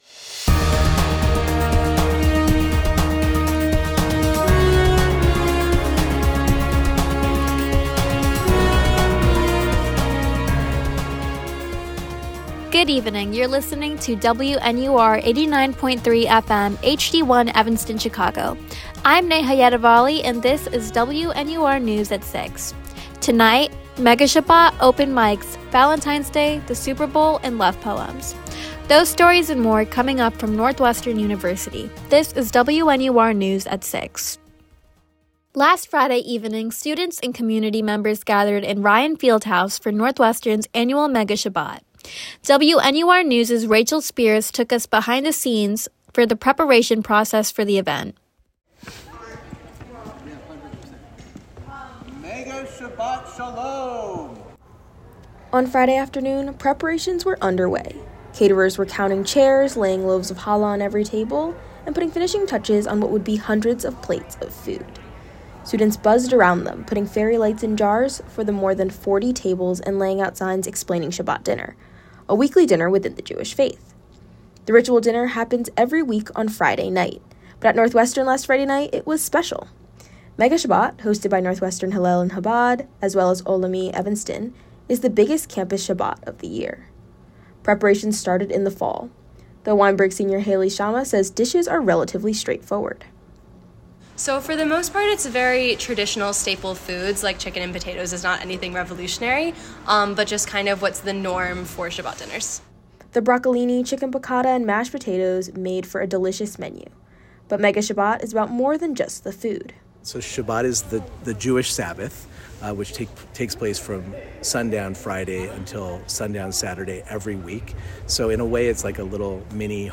WNUR News broadcasts live at 6 pm CST on Mondays, Wednesdays, and Fridays on WNUR 89.3 FM.